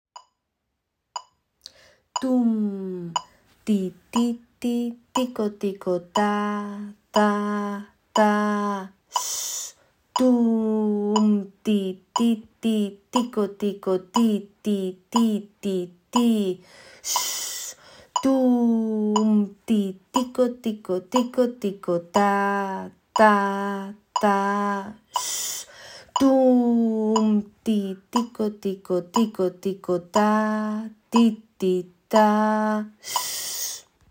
2. Tune and syllables: